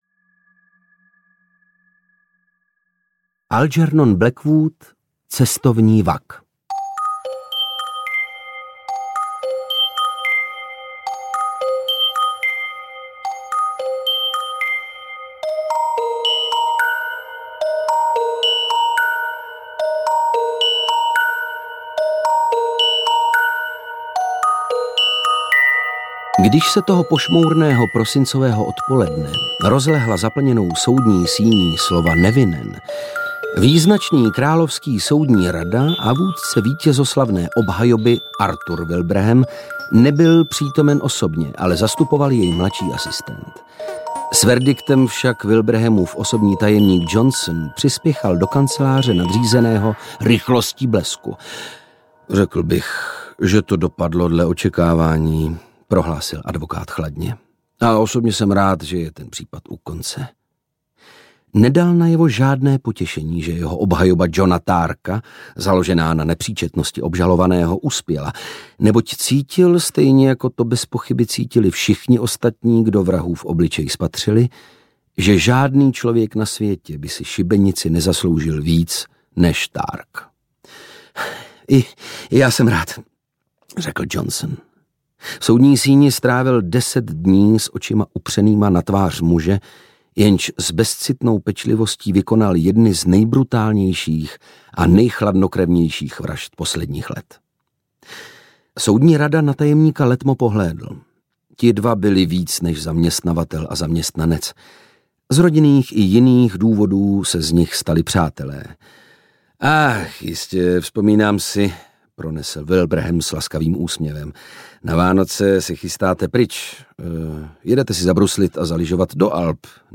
Šťastné a hrůzostrašné audiokniha
Ukázka z knihy
• InterpretOndřej Brousek, Dana Černá